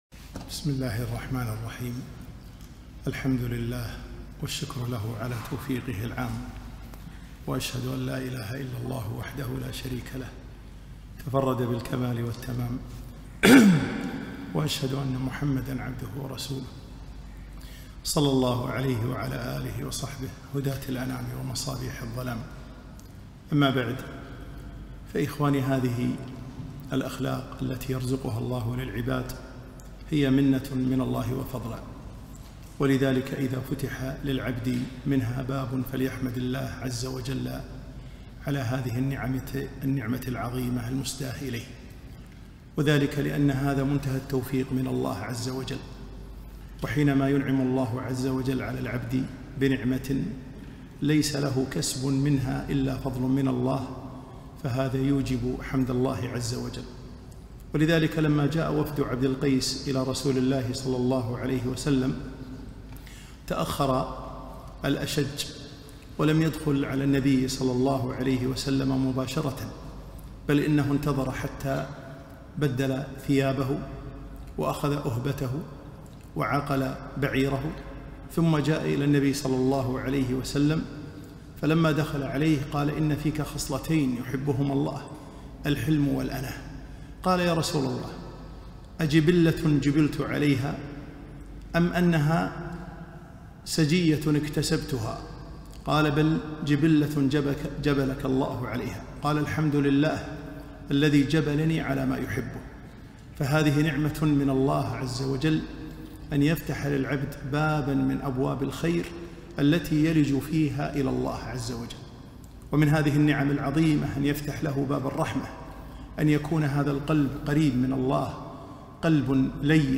محاضرة - الراحمون يرحمهم الرحمن